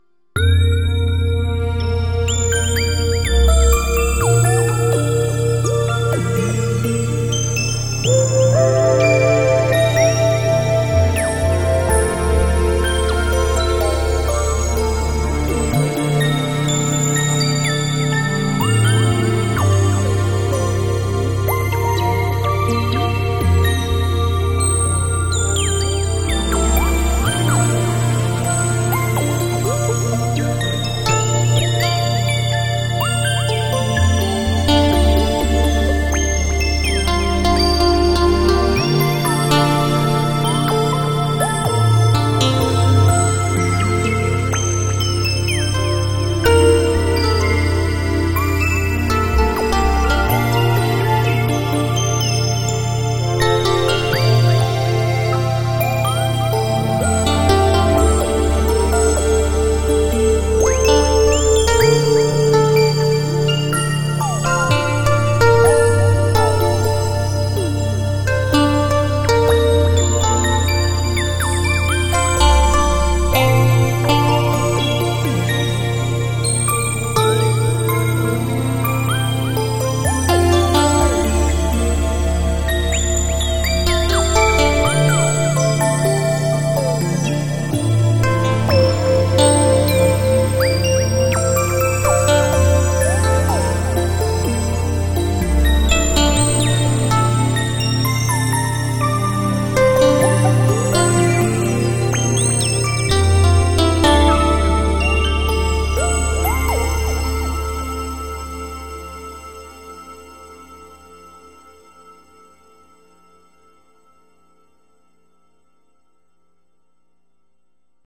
Op-Z + Nord Lead 2 + OP-1 Field.
NL doubling the OP-Z patches for some really rich soundscapes… playing the OP-1 live.